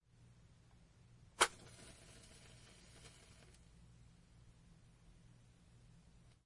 火柴灯2
描述：点燃的声音被点燃。使用Zoom H2n录制。
Tag: 匹配 点燃